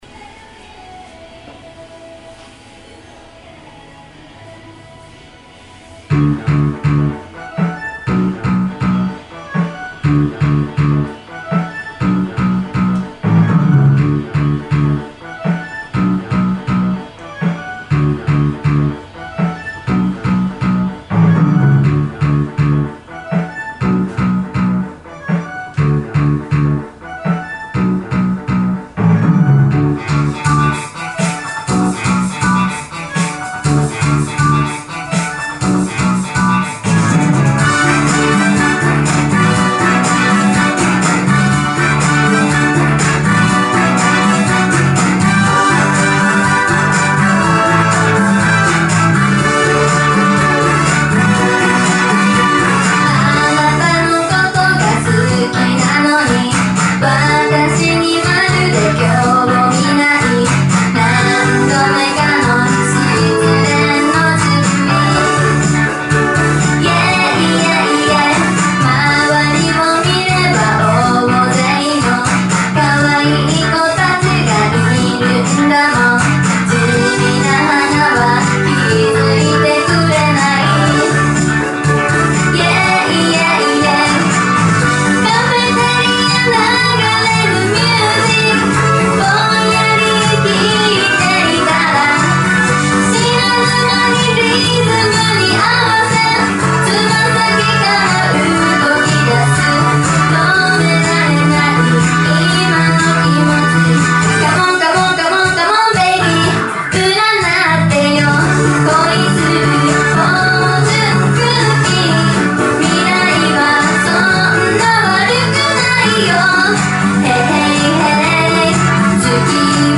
オフマイク／音楽収録用マイクロフォン使用